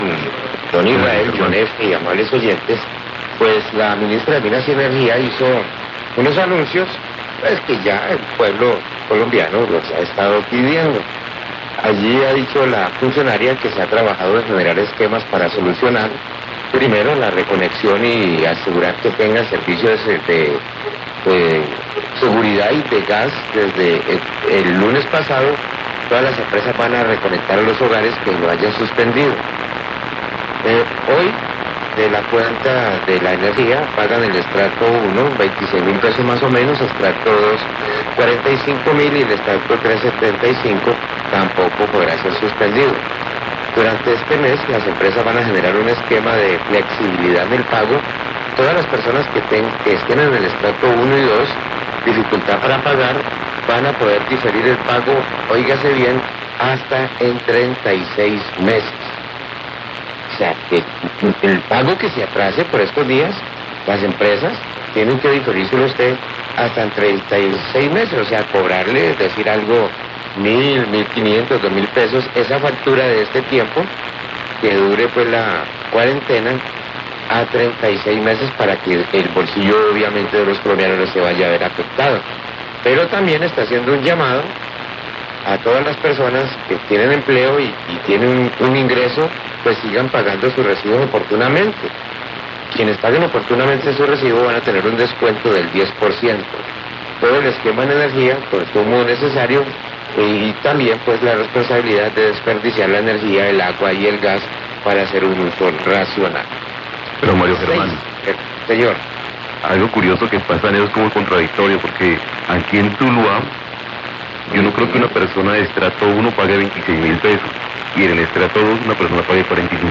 Radio
El presidente de la República, Iván Duque, habló sobre las medidas que se tomaran durante la cuarentena decretada en el país para enfrentar la pandemia del Covid-19.